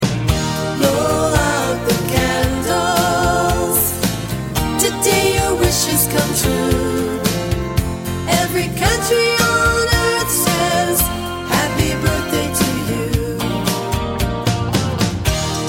A Birthday Song